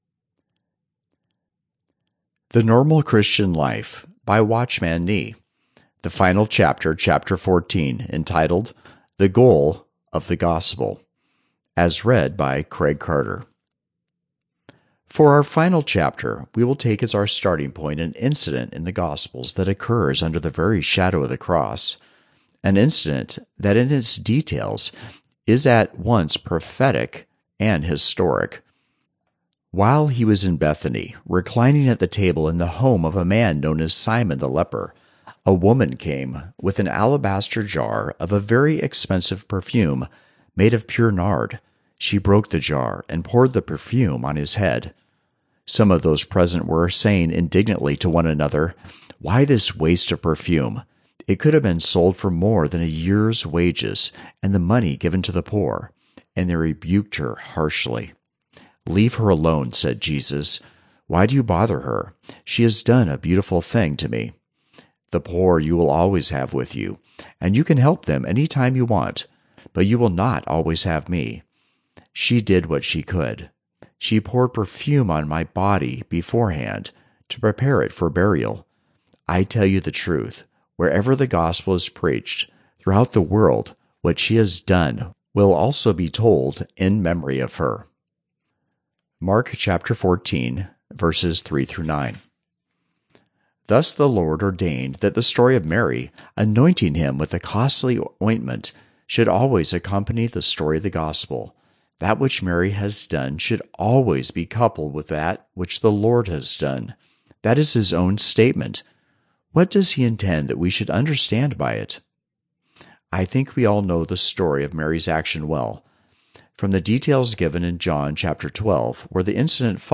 Christmas Songs Word Doc Normal Christian Life - Watchman Nee - Audio, Final Chapter A 33min reading of the final chapter of Watchman Nee’s Book “A Normal Christian Life”. How we are to be wasted for our Lord.